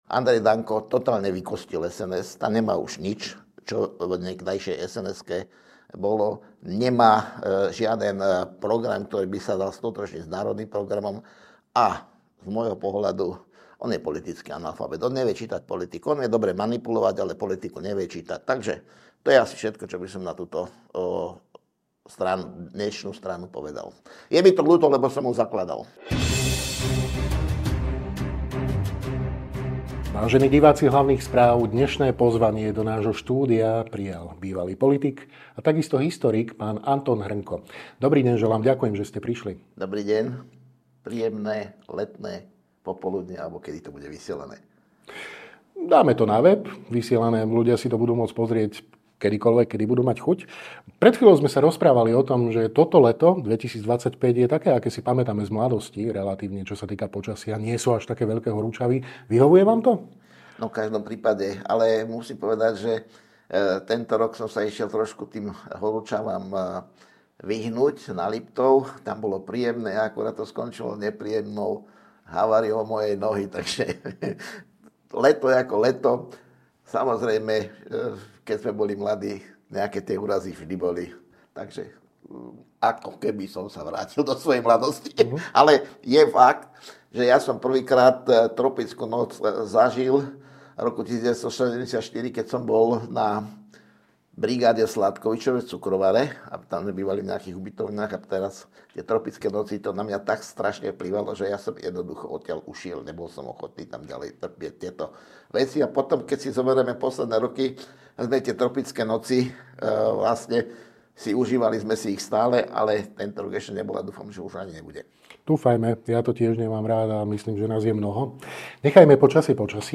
To všetko sú témy videorozhovoru s historikom a bývalým politikom, PhDr. Antonom Hrnkom, CSc.